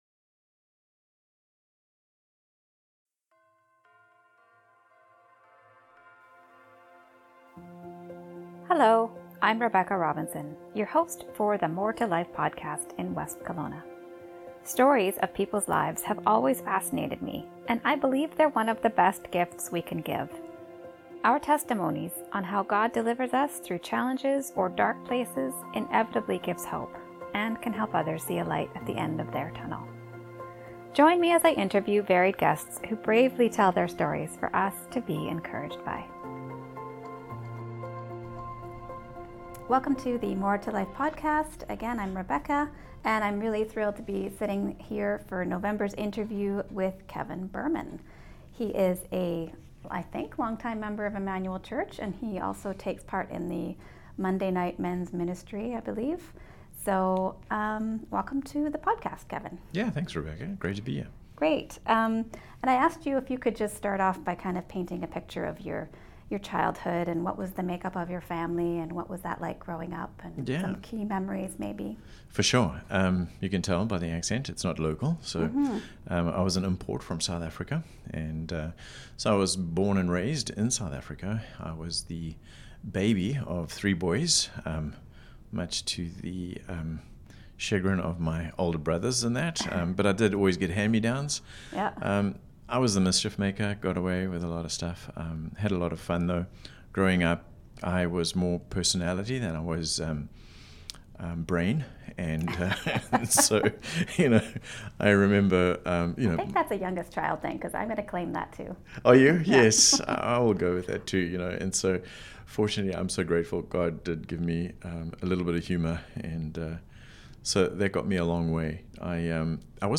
Join me as I interview